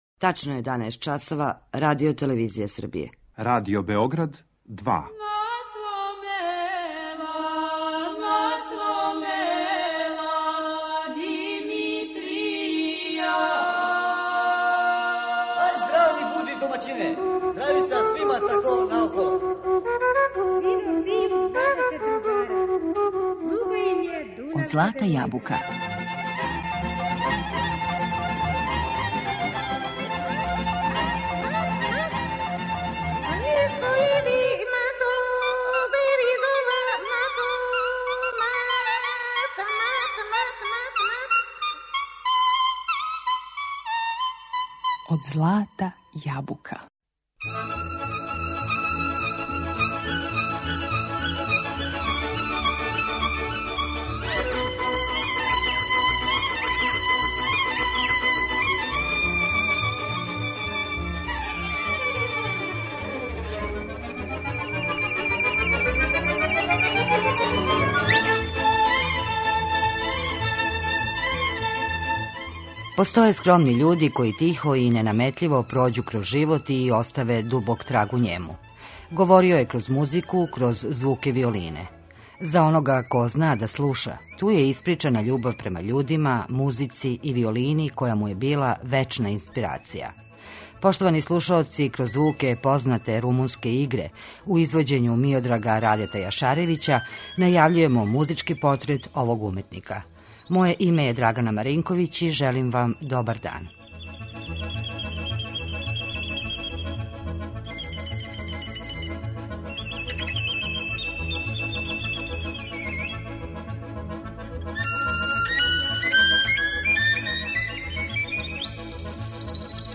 Njegova violina razlikovala se od drugih po zvuku, divnoj melodici i nostalgičnim tonovima.